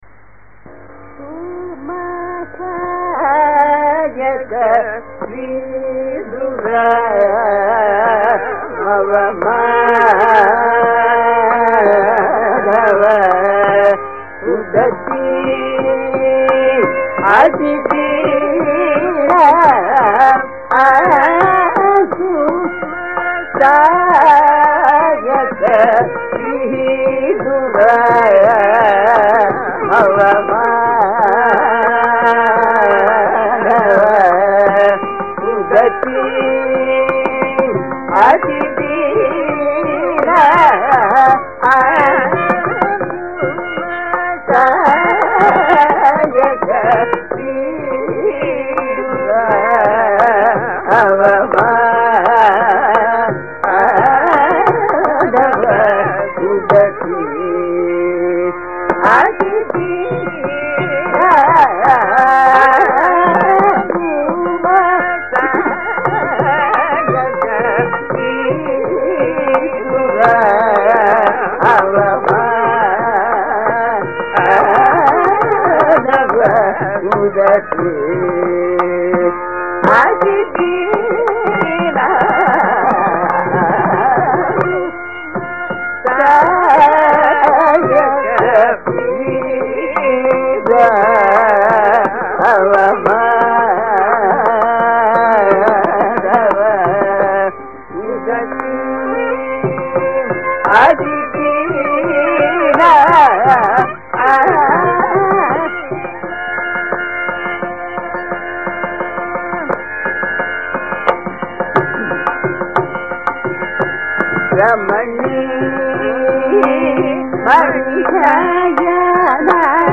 2. Both are set in Karnataka Kapi and in tisra eka tala in the cauka varna format with a pallavi, an anupallavi with muktayi svaras followed by a carana section with multiple ettugada svaras sections thereafter to follow.
2. The final ettugada svara section of sarasAlanu features Hamir Kalyani, Vegavauhini, Vasanta and Mohana; sUmasAyaka instead has Kalyani, Khamas, Vasanta and Mohana.
Luckily, we have a Vidushi in our midst, who had rendered this in a concert in the year 2010 and which was fortuitously recorded. I present the same being the rendering of  Dr Ritha Rajan accompanied by Vidvans R K Sriramkumar and K Arun Prakash on the violin and mrudangam respectively.
This is from the concert she gave for ‘Nada Inbam’ on 30-Aug-2010 at the Raga Sudha Hall, Chennai (See Foot note 2).